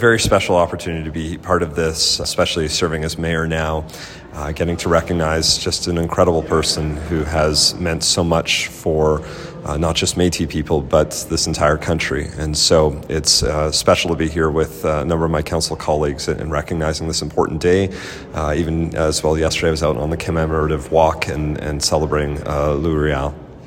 Edmonton’s newly elected Mayor Andrew Knack tells CFWE it was a special opportunity to take part in his first-ever event, as well as join in the Commemorative Walk during Métis week.
lois-riel-commemorative-ceremony-audio-clip-02.mp3